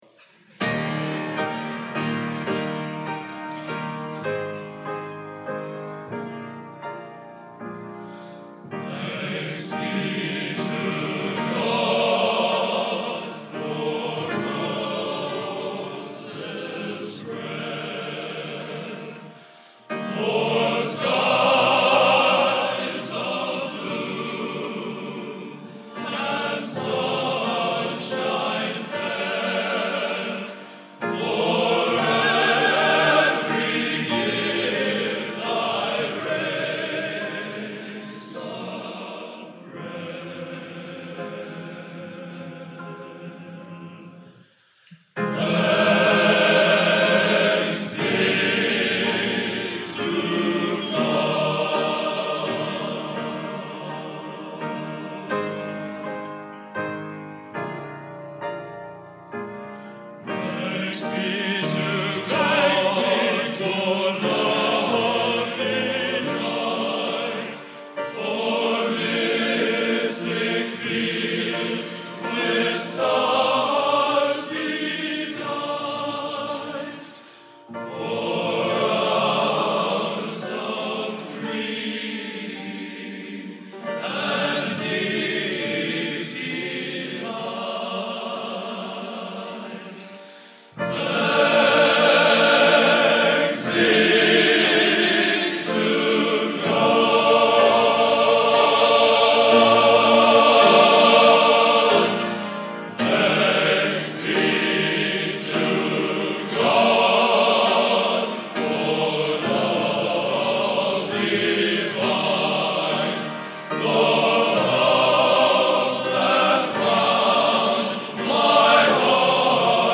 Genre: Modern Sacred | Type: